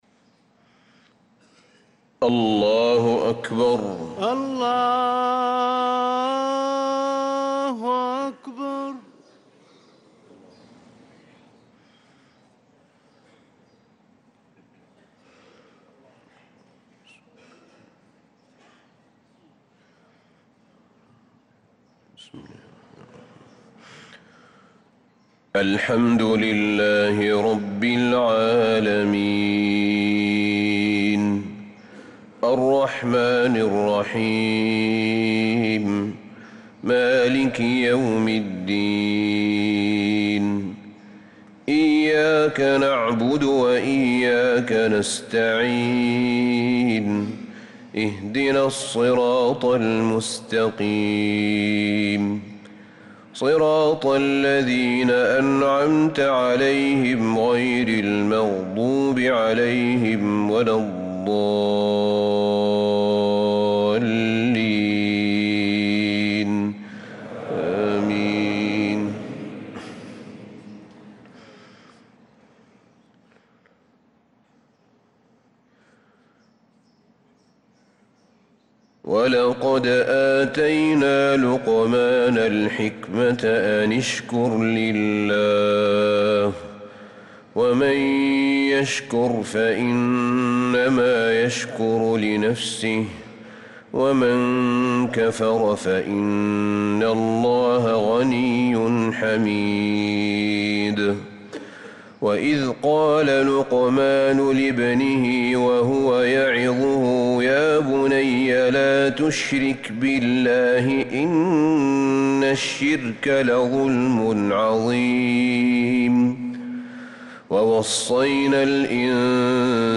صلاة الفجر للقارئ أحمد بن طالب حميد 16 رجب 1446 هـ
تِلَاوَات الْحَرَمَيْن .